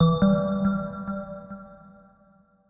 shutter.wav